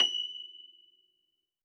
53r-pno24-F5.wav